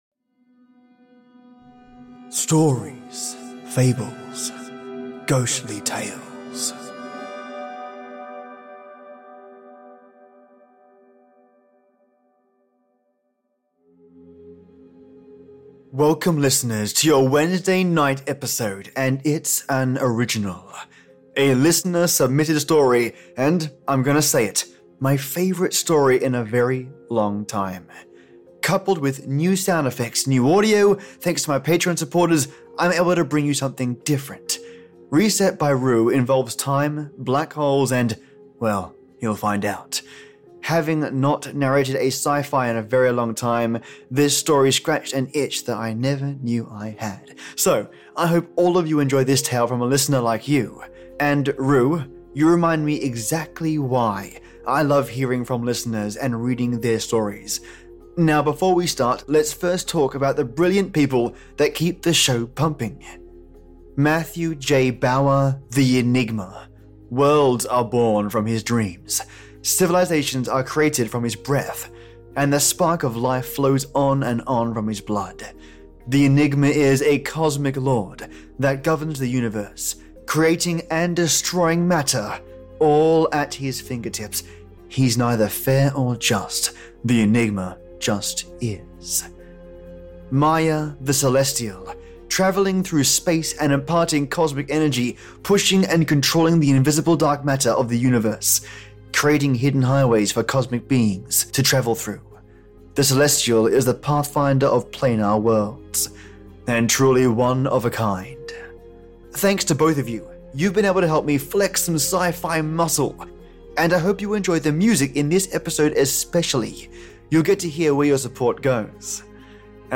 Coupled with new sound effects, new audio thanks to my Patreon supporters, I’m able to bring you something different.
Having not narrated a sci-fi in a very long time, this story scratched an itch that I never new I had.